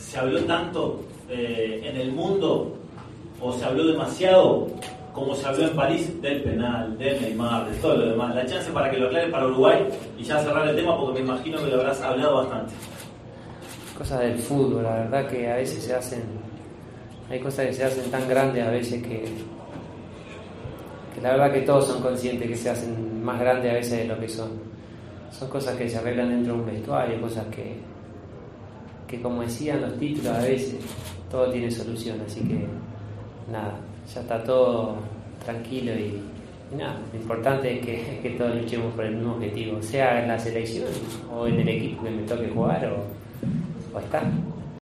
El delantero uruguayo habló desde la concentración con su selección de la relación con Neymar y el asunto de los penaltis: "Son cosas del fútbol. Se hacen cosas tan grandes a veces de lo que son cosas que se arreglan dentro de un vestuario, todo tiene solución. Ya está todo tranquilo. Lo importante es luchar por el mismo objetivo".